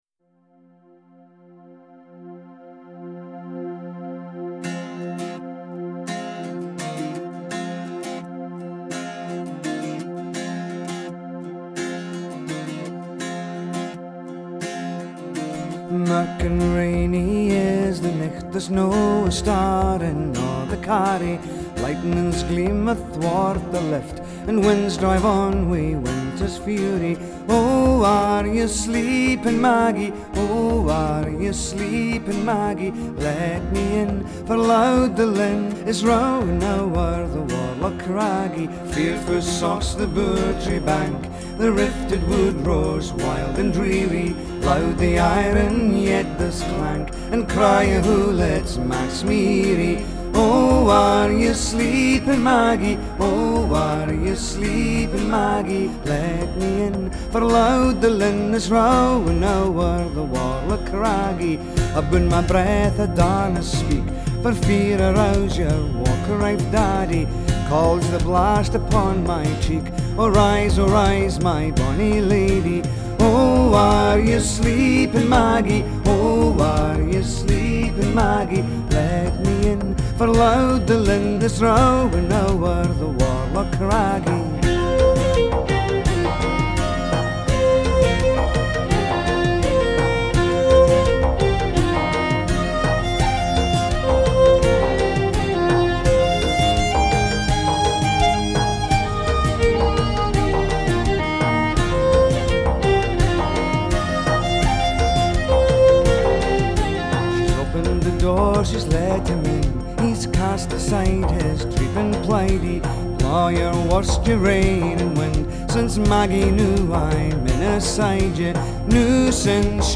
Genre: Folk / World /Celtic